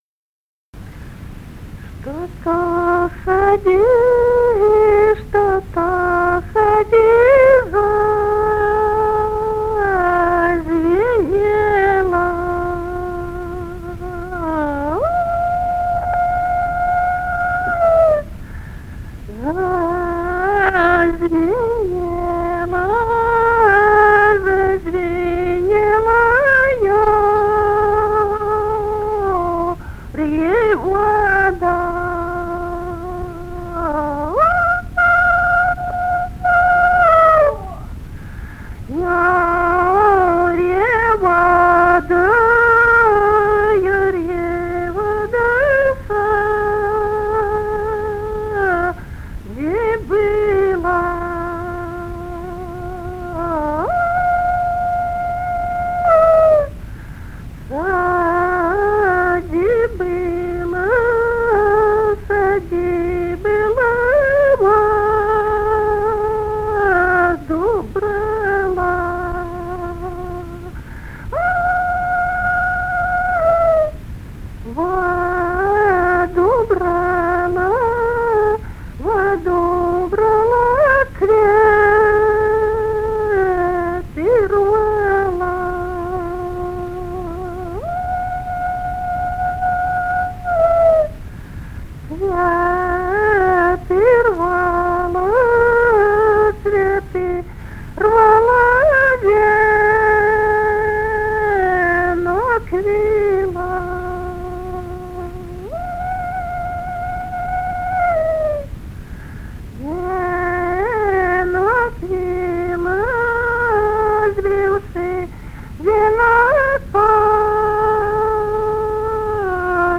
Народные песни Стародубского района «Что-то в саде зазвенело», юрьевская таночная.